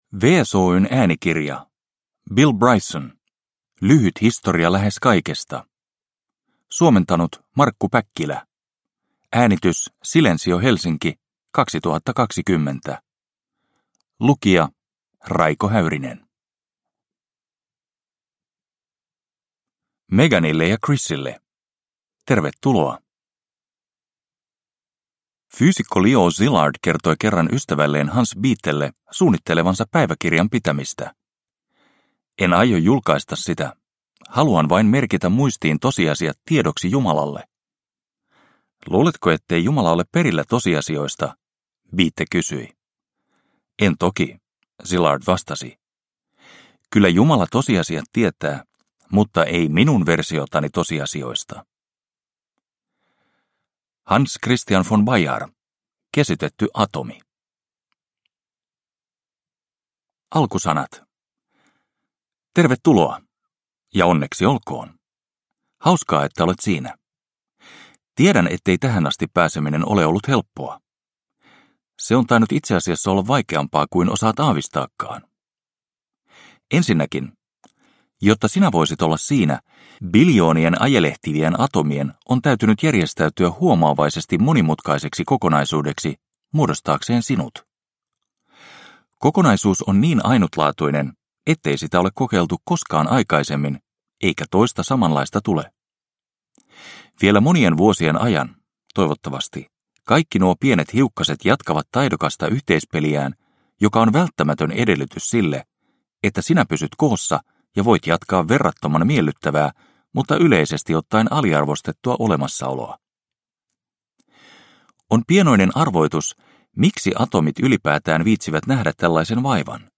Lyhyt historia lähes kaikesta – Ljudbok – Laddas ner